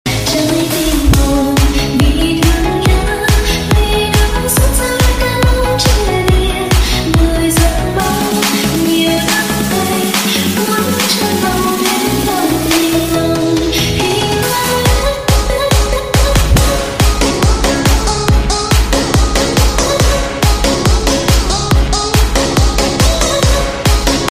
Nhạc Chuông TikTok